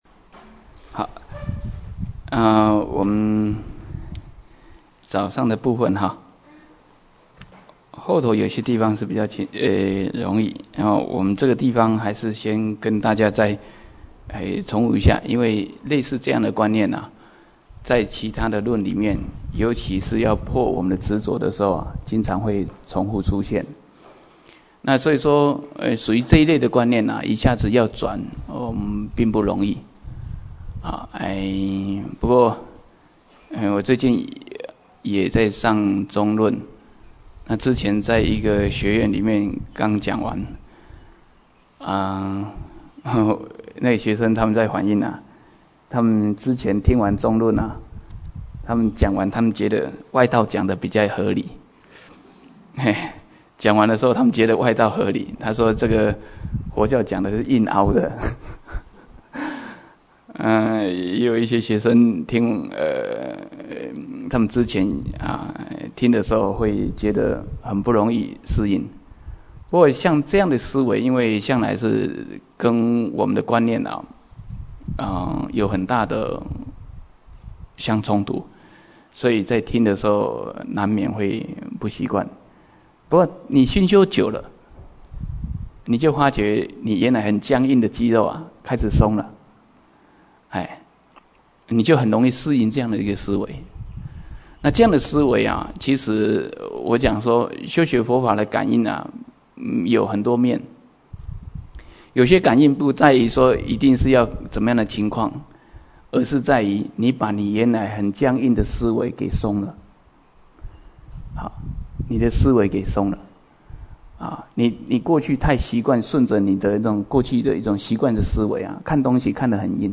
瑜伽师地论摄抉择分027(音軌有損.只35分).mp3